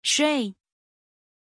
Aussprache von Shay
pronunciation-shay-zh.mp3